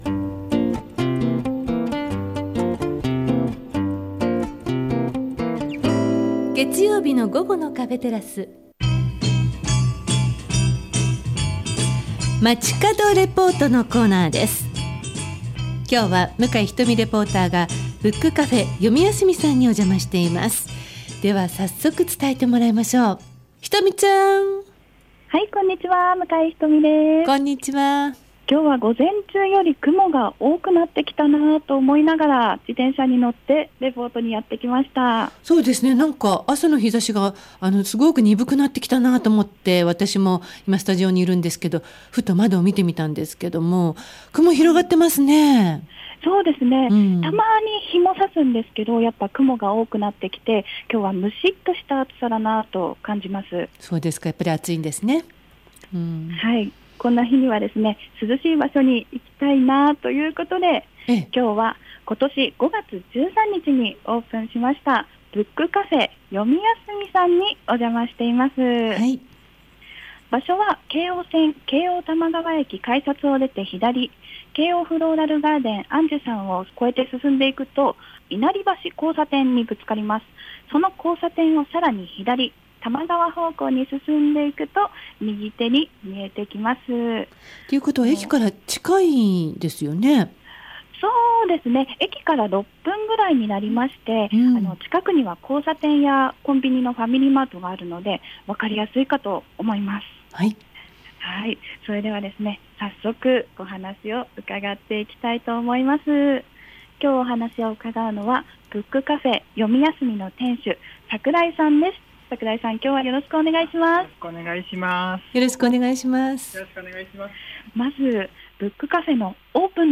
午後のカフェテラス 街角レポート
、 今日、明日は、台風の影響にお気をつけください♪ さて、 今日は、京王多摩川駅から徒歩約6分、 ブックカフェ ヨミヤスミ さんからお届けしました！！